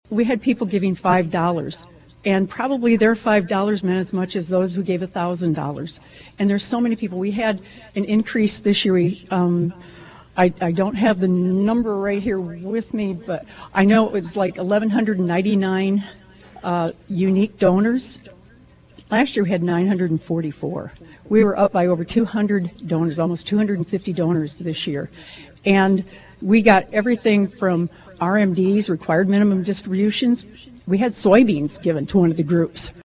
There were signs of a massive Match Day before Tuesday’s official check presentation ceremony — but most people on hand were totally shocked at the individual check amounts and the final total announced at Flinthills Mall as part of live coverage on KVOE.